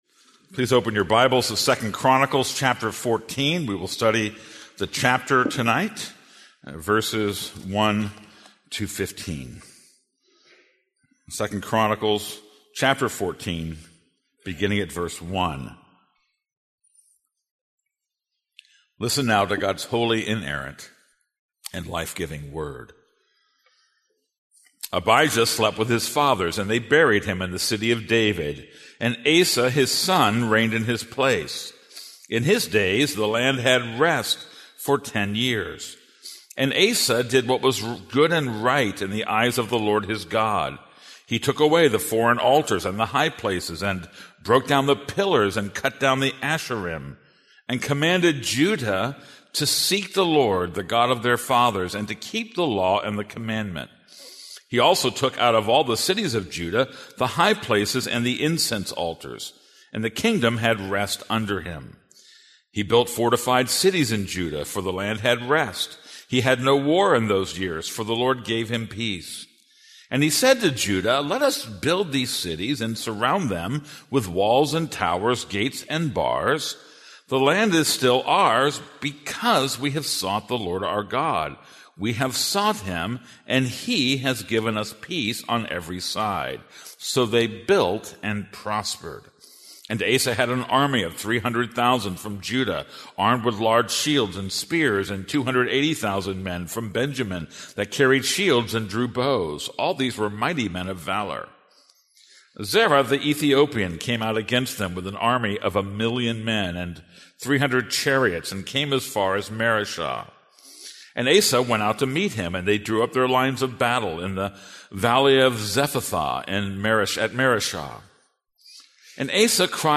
This is a sermon on 2 Chronicles 14:1-15.